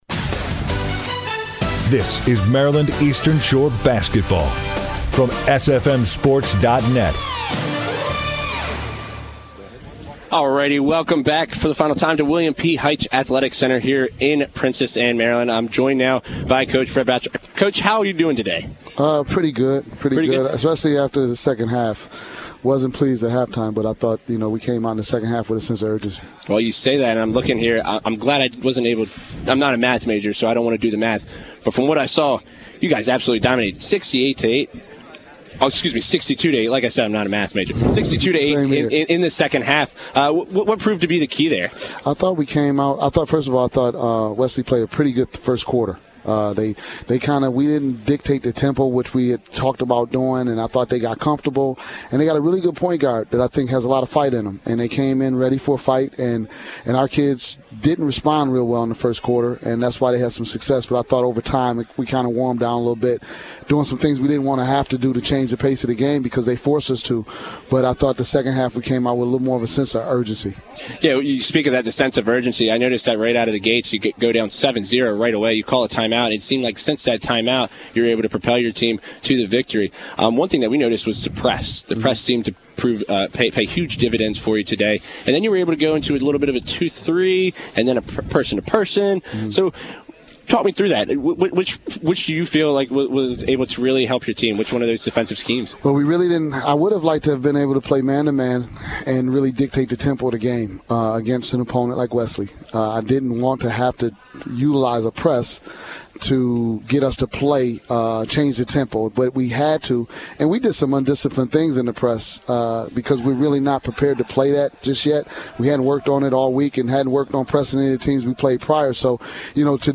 Post Game Interview